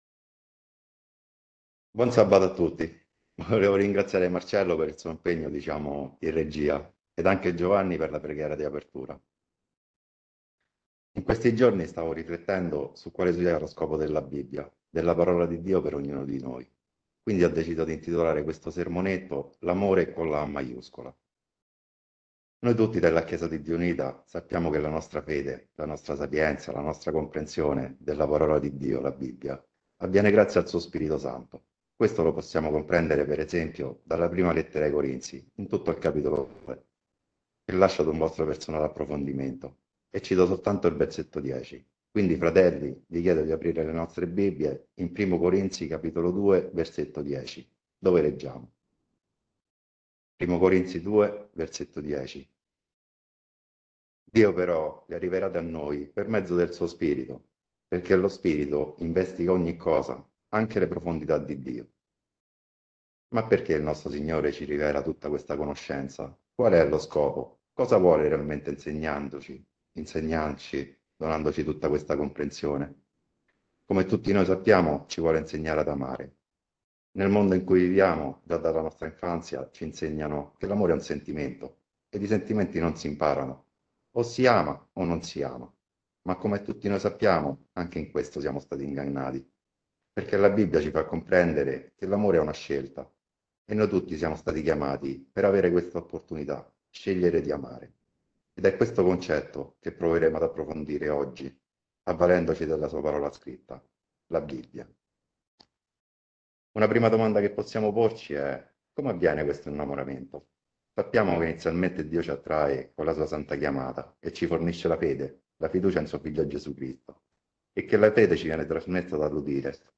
L’amore con la A maiuscola – Sermonetto